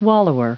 Prononciation du mot wallower en anglais (fichier audio)
Prononciation du mot : wallower